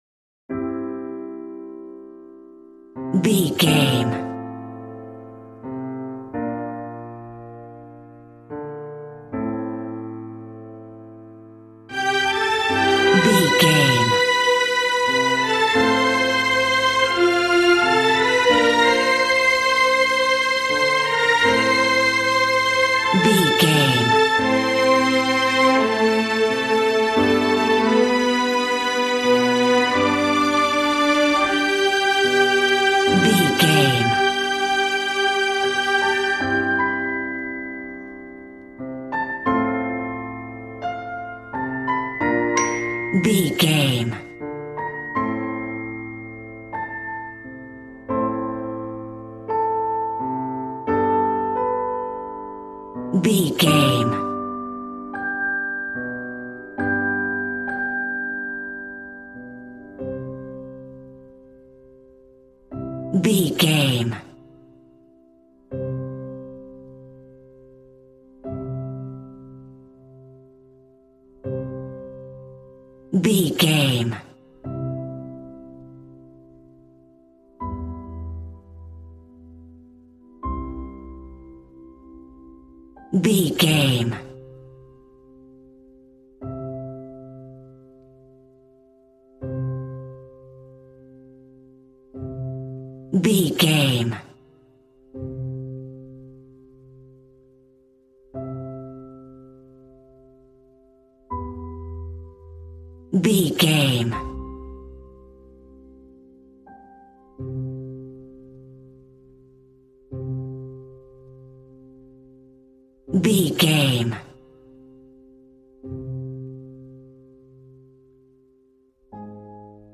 Regal and romantic, a classy piece of classical music.
Ionian/Major
violin
brass